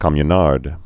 (kŏmyə-närd)